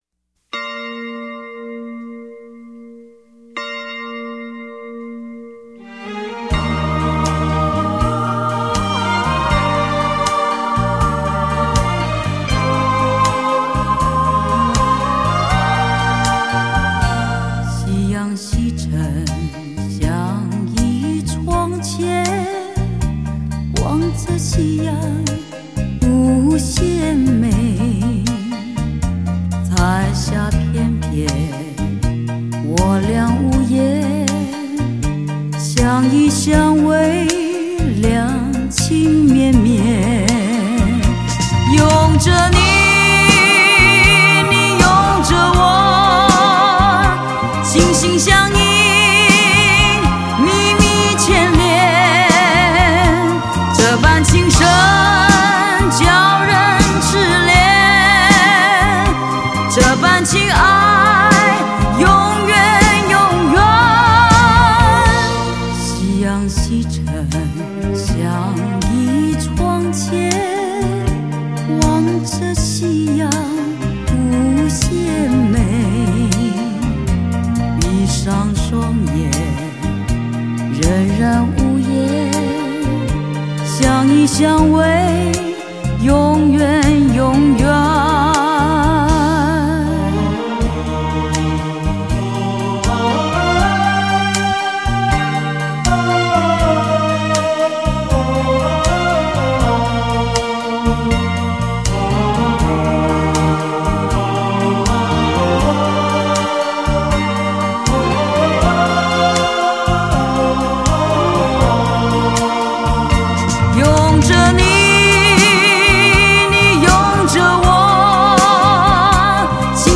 优异的唱腔与细腻的转折，展现巨星风范，精彩优美的编曲，更让人回味无穷！